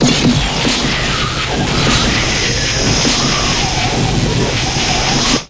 Thruster Quick, Reversed Loop